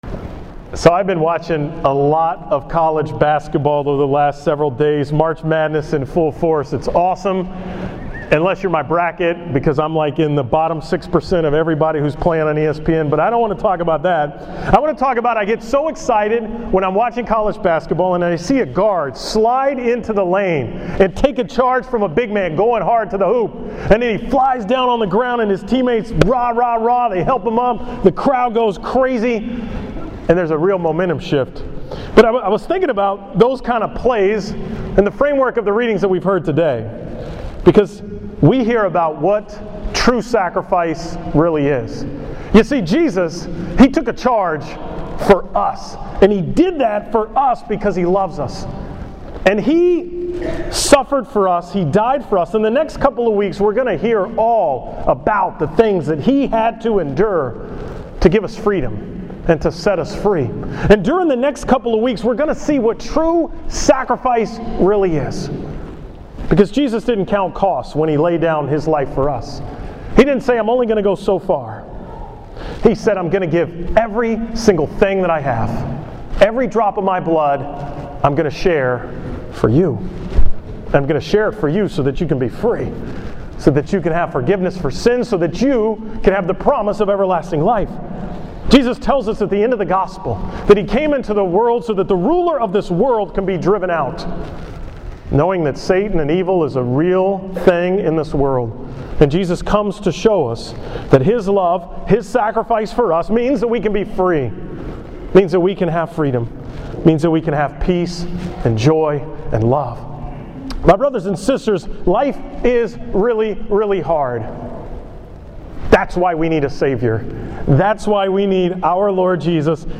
From the 11 am Mass at St. Vincent De Paul on March 22, 2015 (5th Sunday of Lent)